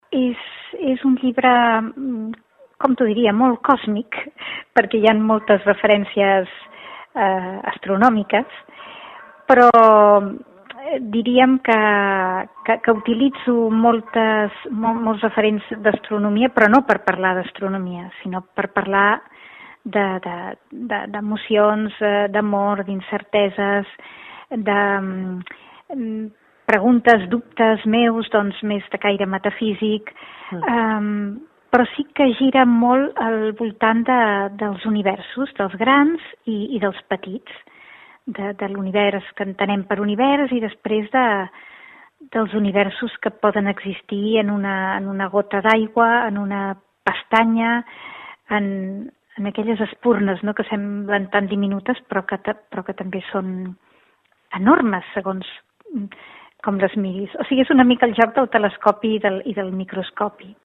Ella mateixa descrivia el seu llibre.